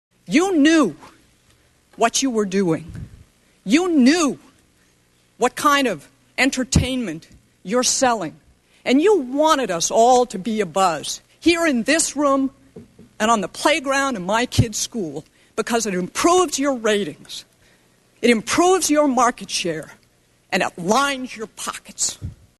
Just listen to her quaky-voice, teary-eyed
testimony (mp3) about the Jackson incident at a conservative showcase Congressional hearing.